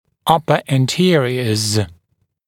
[‘ʌpə æn’tɪərɪəz][‘апэ эн’тиэриэз]передние зубы верхней челюсти